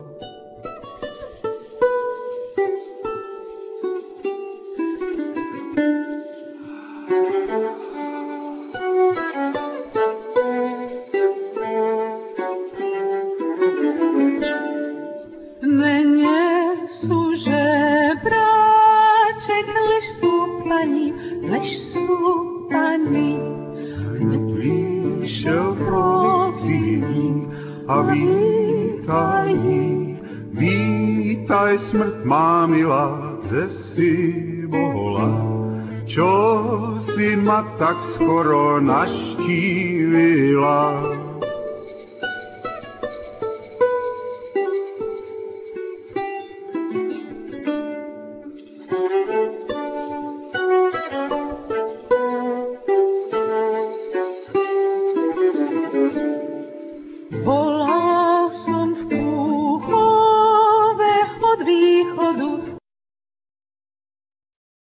Vocals,Viola
Voclas,Guitar,Lute,Duduk,Harmonica,Percussions,etc
Viola da ganba
Percussions